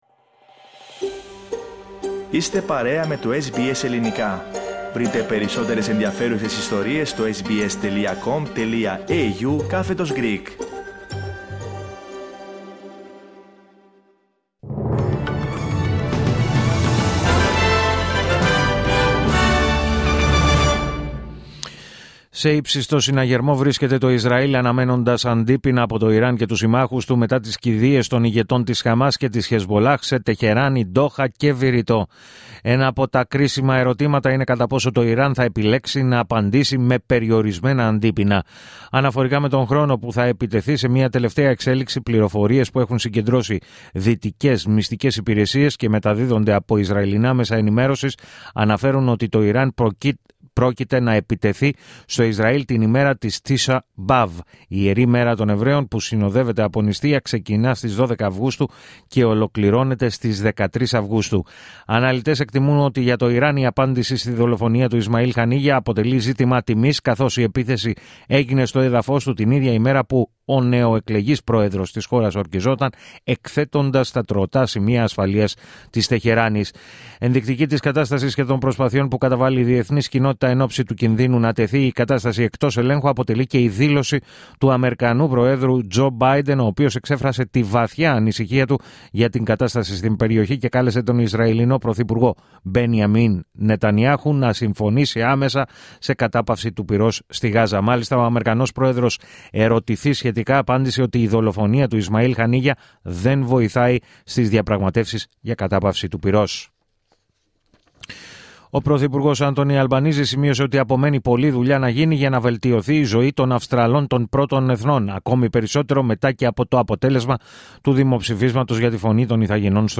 Δελτίο Ειδήσεων Σάββατο 3 Αυγούστου 2024